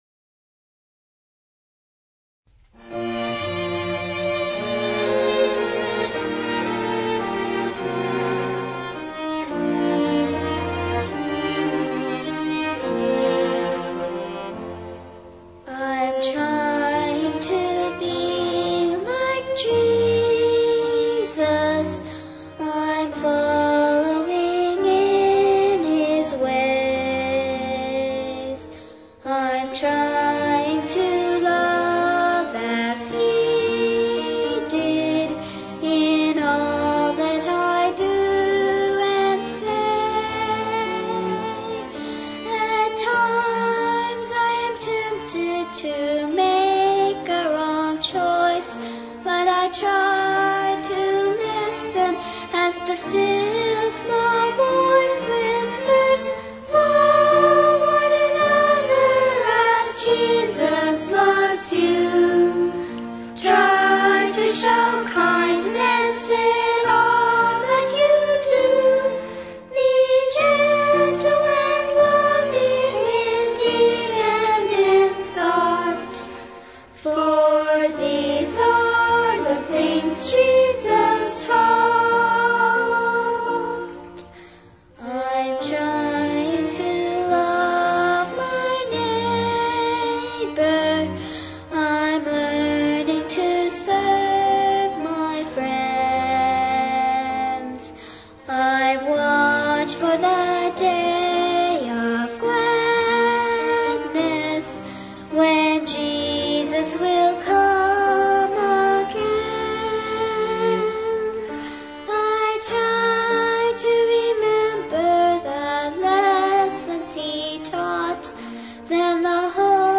惟請注意，以下檔案大部分為Midi檔，其音質不及原裝音樂。
教會詩歌：